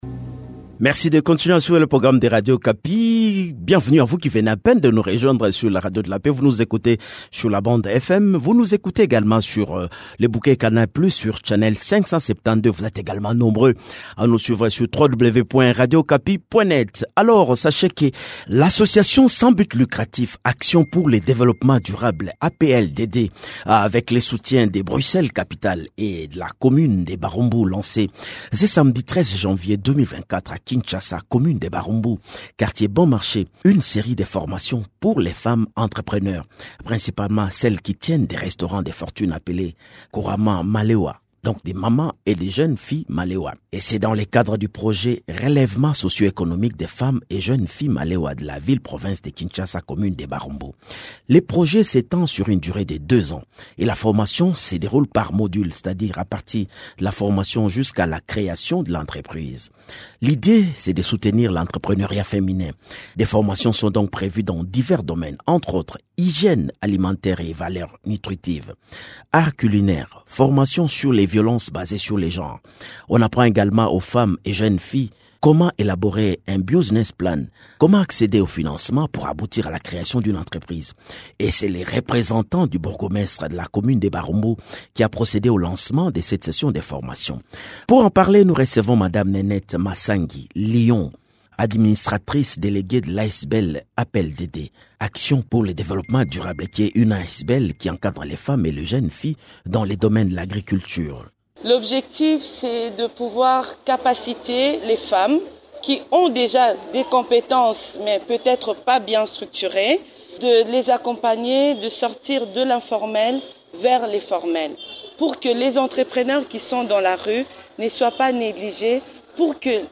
Elle a effectué ce lancement dans la commune de Barumbu, à Kinshasa.